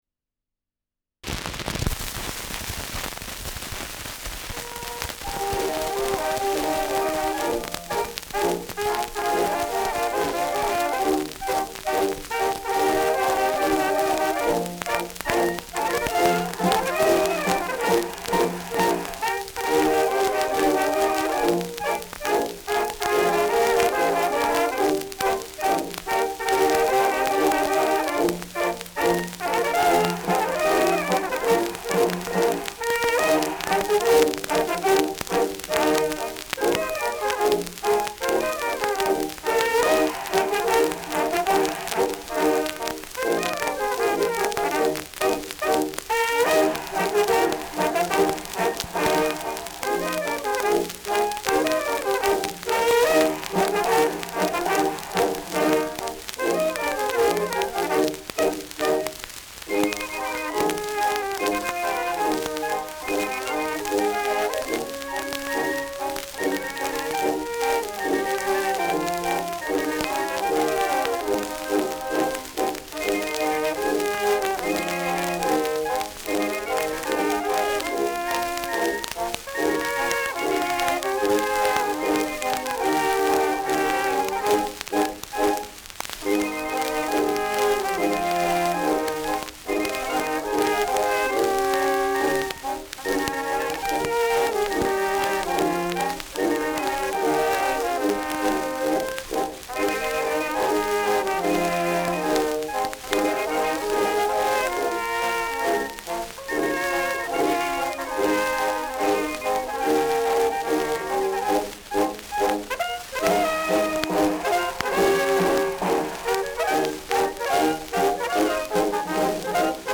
Schellackplatte
Starkes Grundknistern